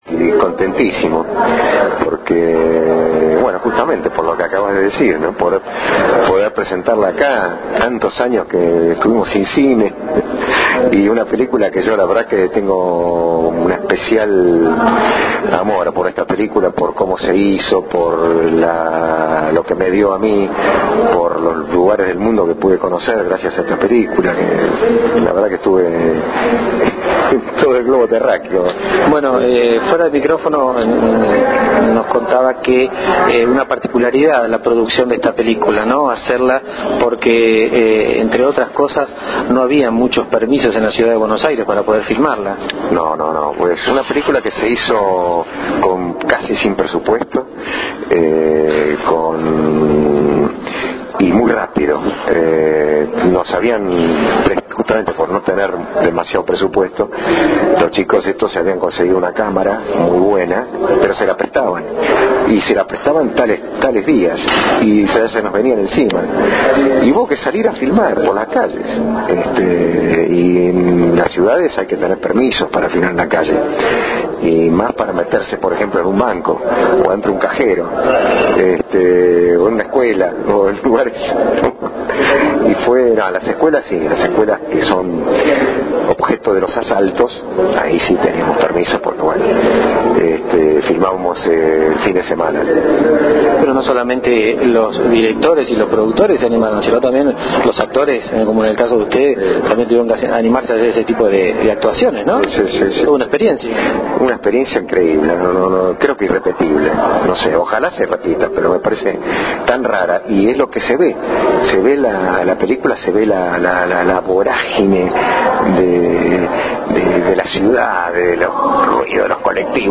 Antes de la proyección hablamos con el reconocido actor, de sangre fernandense quien visiblemente emocionado dijo estar muy contento por esta situación.
Audio de Arturo Goetz